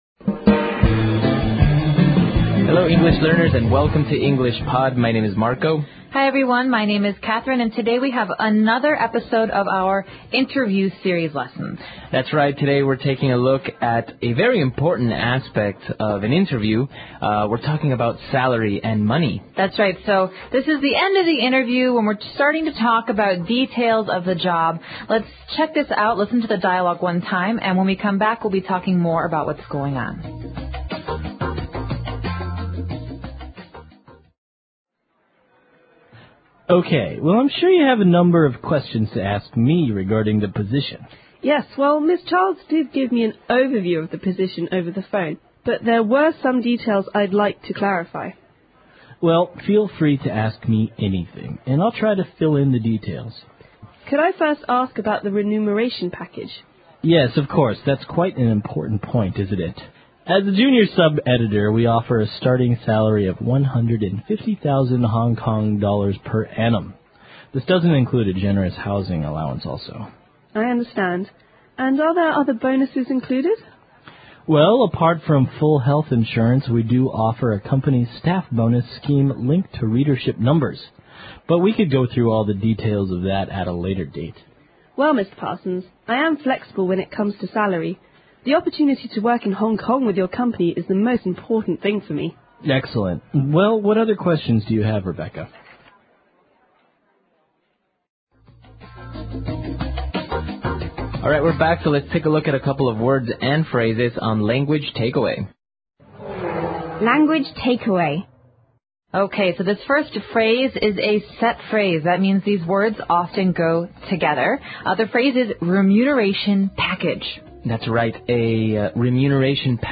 纯正地道美语(外教讲解)247：询问薪酬 听力文件下载—在线英语听力室